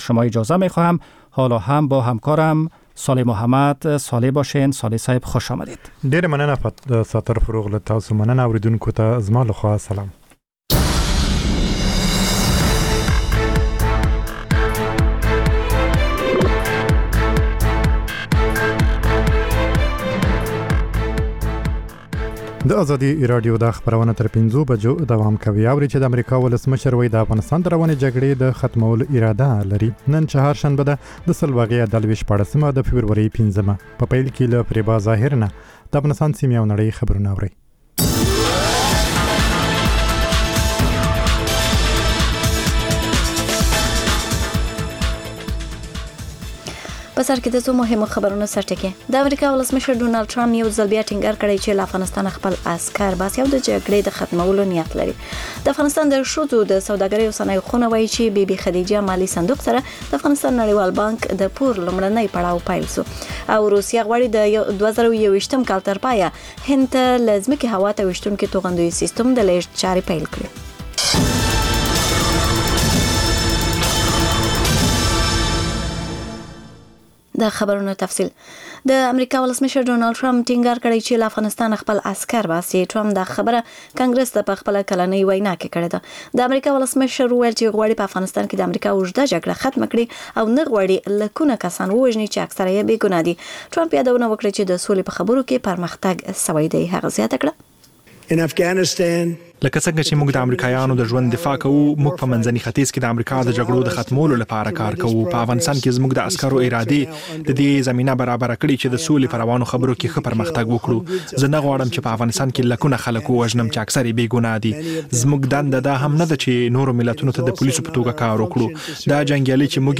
خبرونه او راپورونه